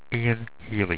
Pronounced
EE-AN HEELEE